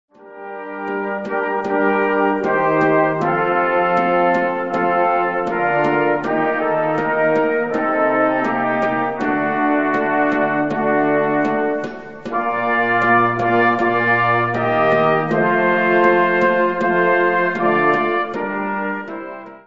Catégorie Harmonie/Fanfare/Brass-band
Sous-catégorie Suite